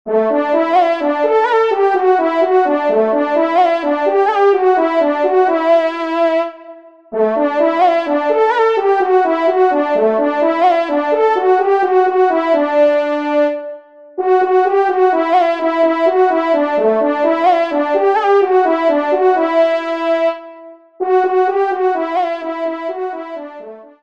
Solo Trompe      (Ton de vénerie)